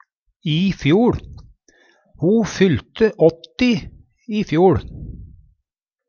i fjoL - Numedalsmål (en-US)